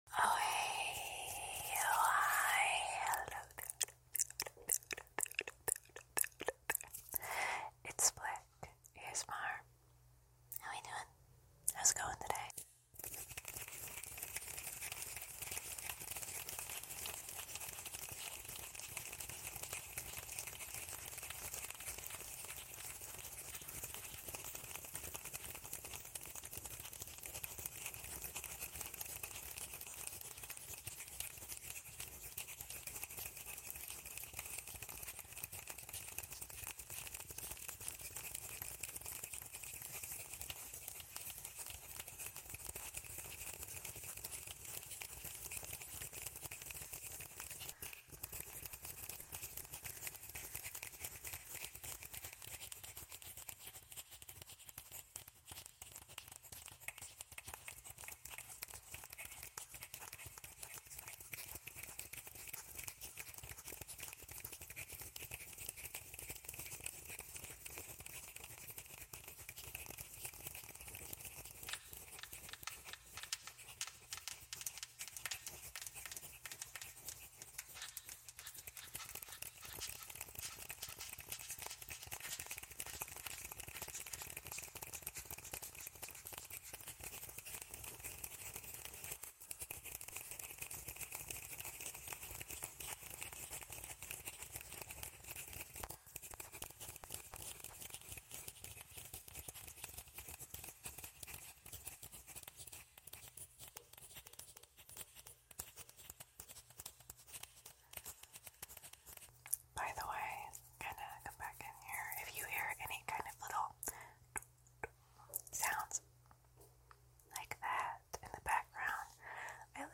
Echoed Snapping (Visual and Audio sound effects free download
Echoed Snapping (Visual and Audio Delay Plus Reverb)